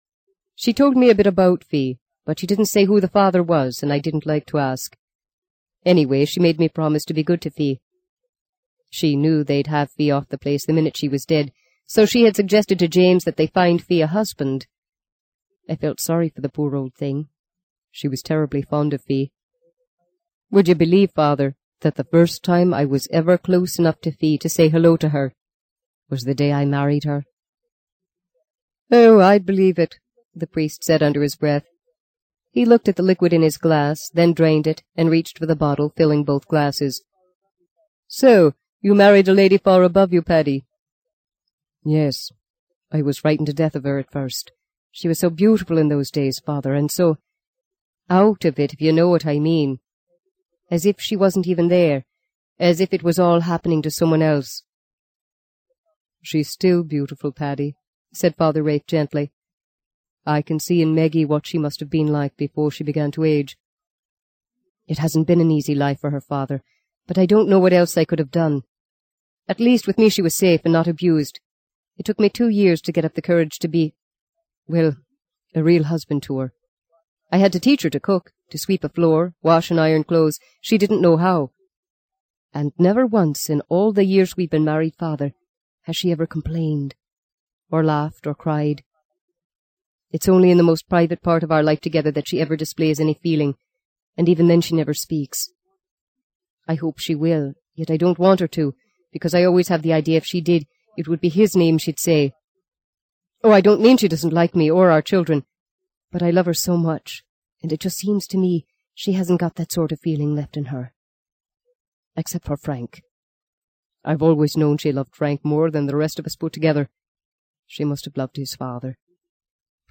在线英语听力室【荆棘鸟】第五章 13的听力文件下载,荆棘鸟—双语有声读物—听力教程—英语听力—在线英语听力室